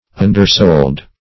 Undersold \Un`der*sold"\